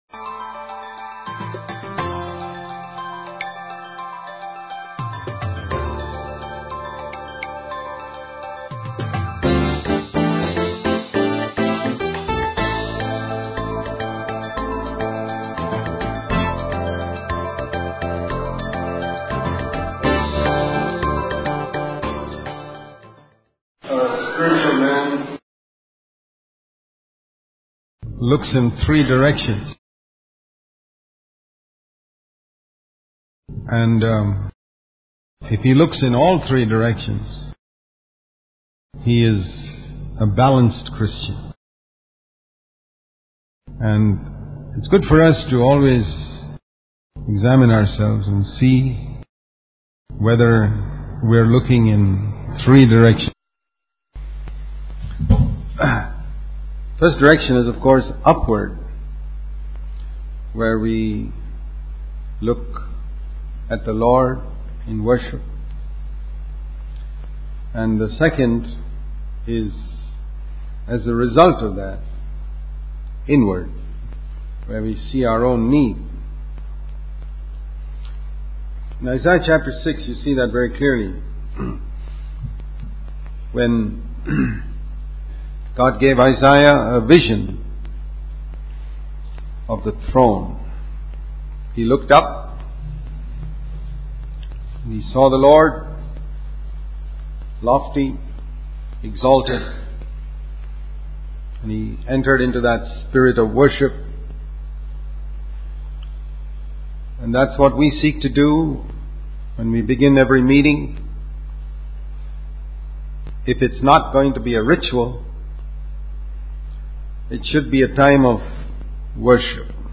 In this sermon, the speaker emphasizes the importance of bringing children to Sunday school to protect them from negative influences like drugs and alcohol.